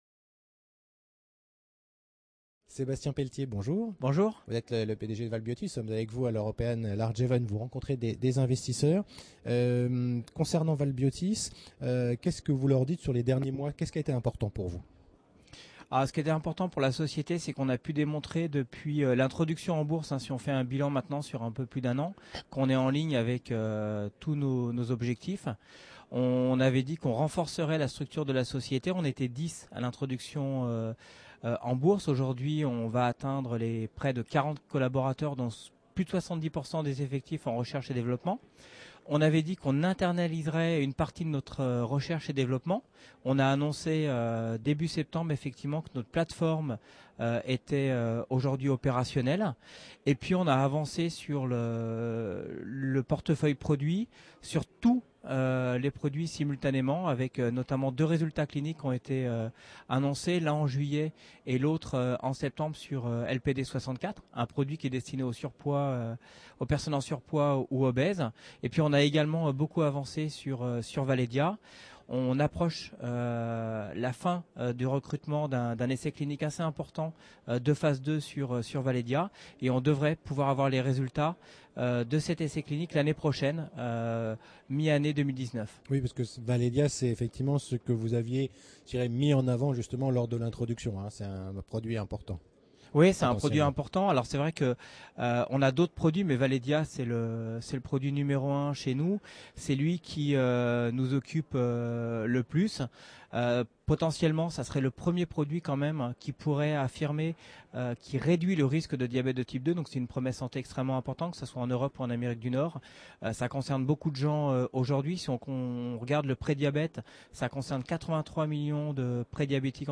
La Web Tv rencontre les dirigeants au Paris - European Large et Midcap Event